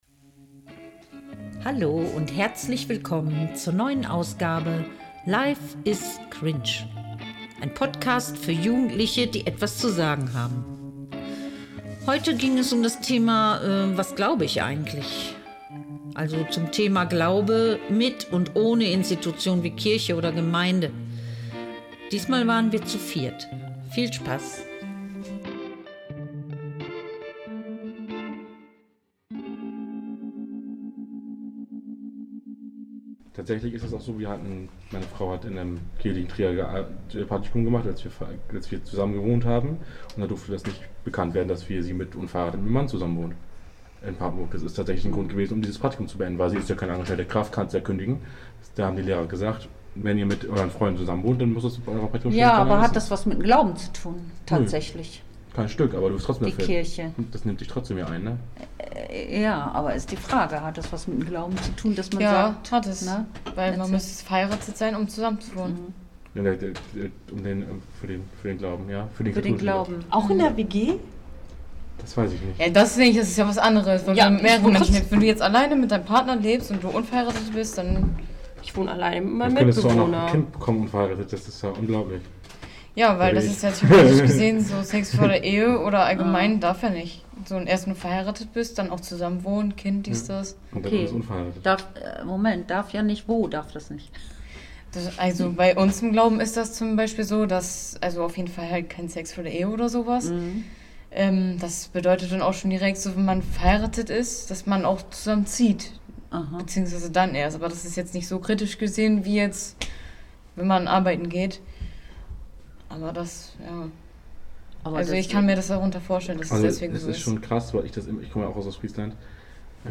Im Gespräch zum Thema Glaube.